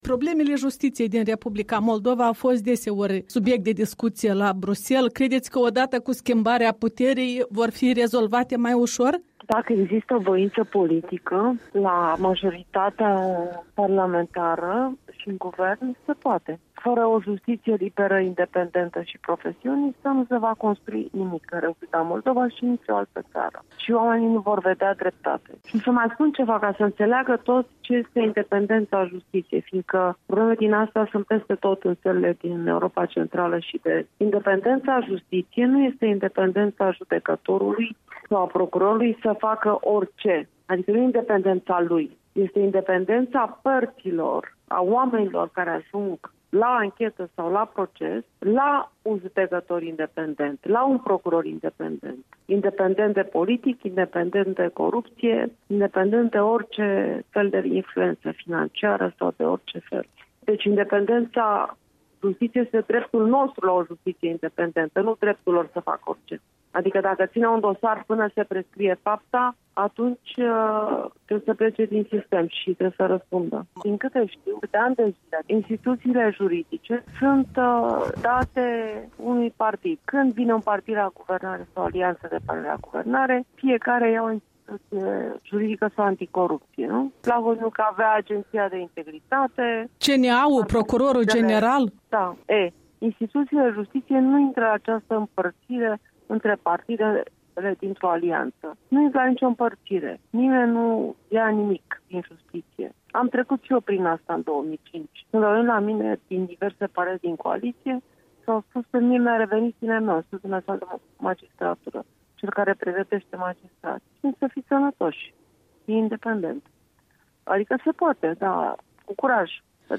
Un interviu cu eurodeputata Monica Macovei